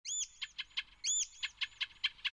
warbler.mp3